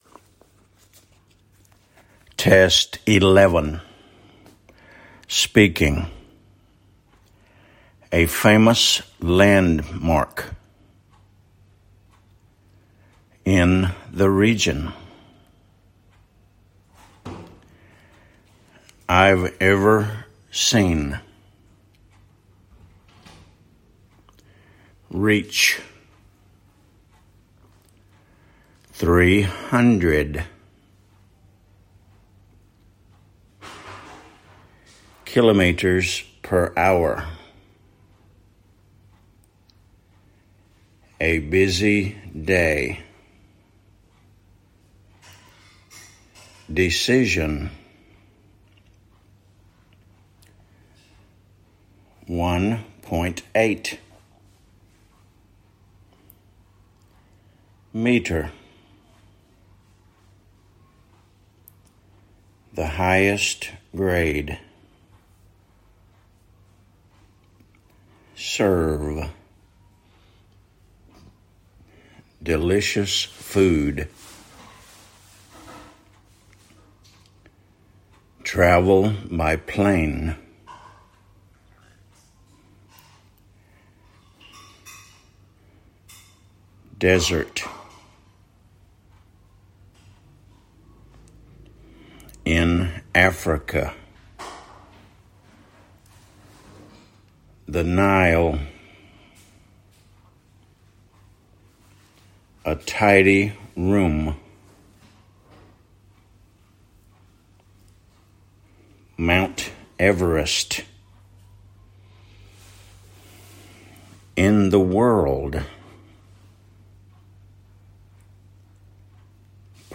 Test 6211: Speaking